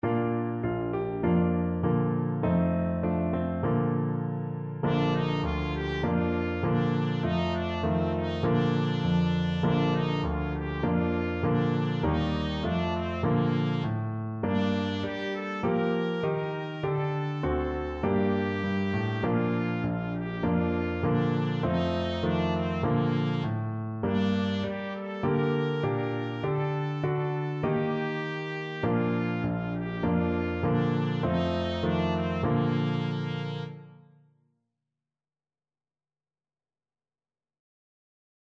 4/4 (View more 4/4 Music)
Bb4-Bb5
Israeli